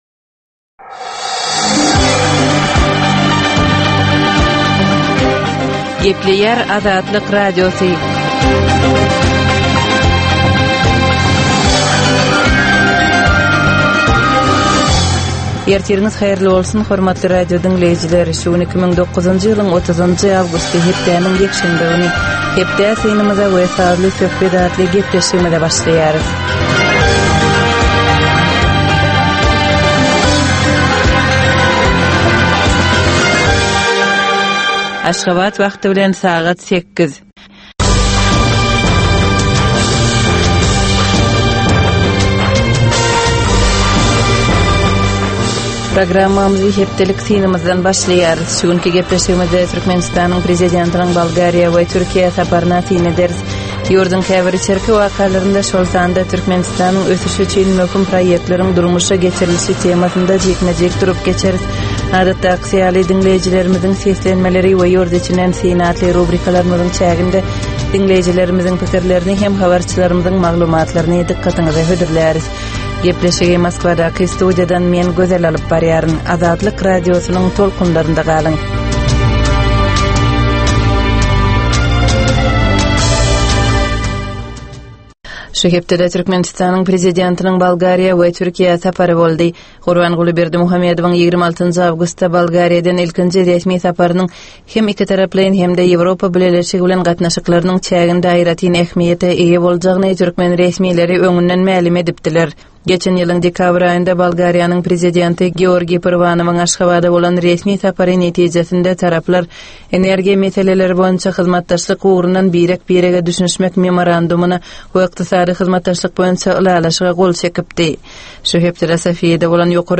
Tutus geçen bir hepdänin dowamynda Türkmenistanda we halkara arenasynda bolup geçen möhüm wakalara syn. 30 minutlyk bu ýörite programmanyn dowamynda hepdänin möhüm wakalary barada gysga synlar, analizler, makalalar, reportažlar, söhbetdeslikler we kommentariýalar berilýar.